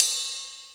D2 RIDE-08-R.wav